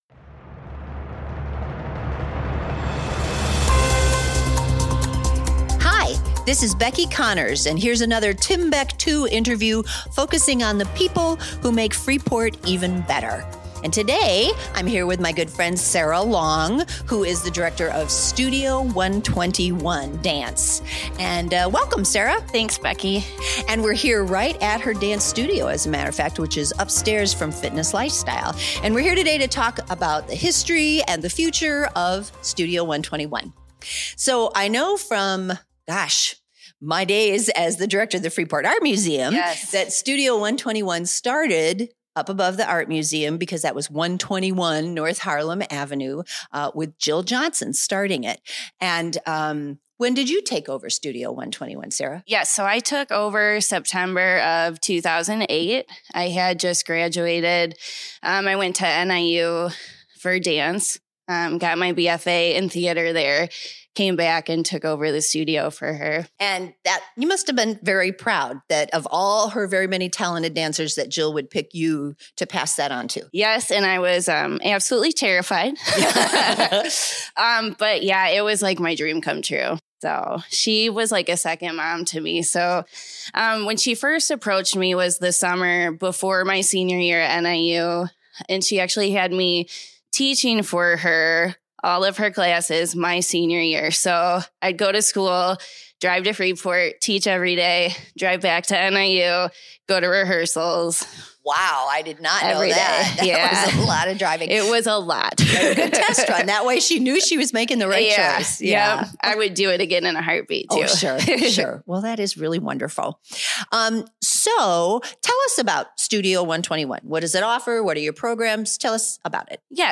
Freepod Interview